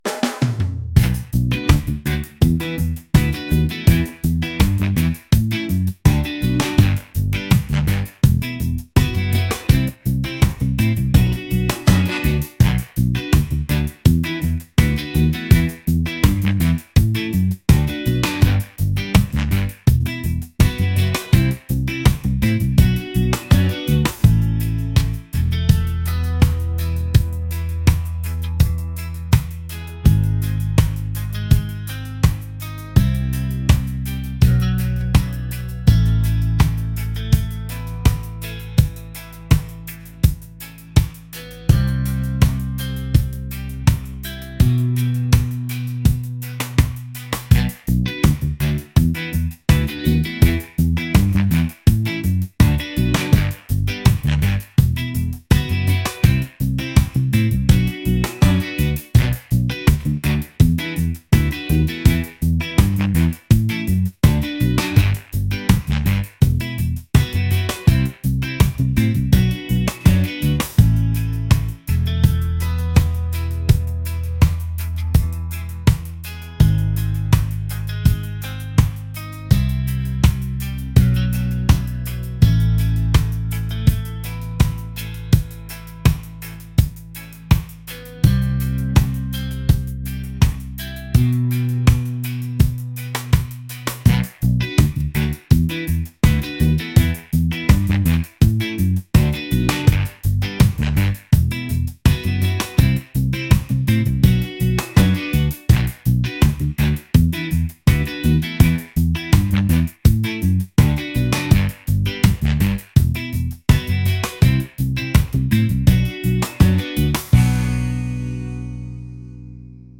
laid-back | reggae | ska